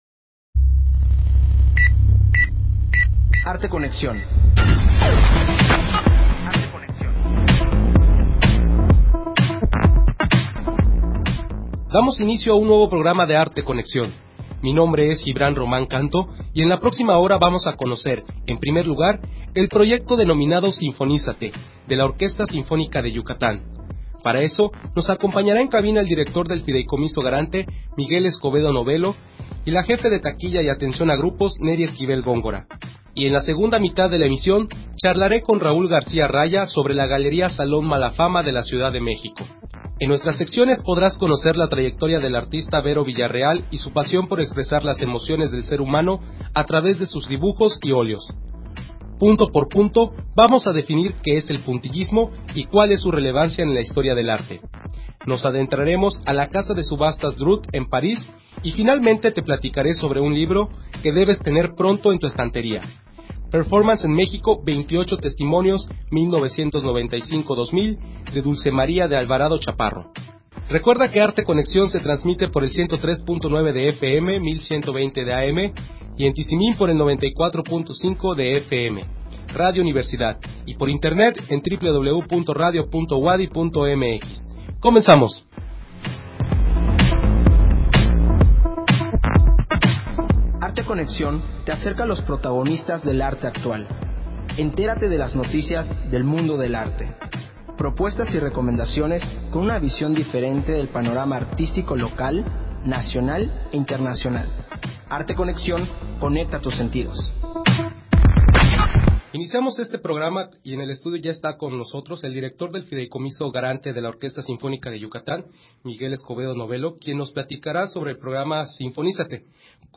Entrevista sobre "Sinfonízate" de la OSY y la Galería Salón Malafama / Parte 1
Emisión de Arte Conexión transmitida el 16 de febrero del 2017.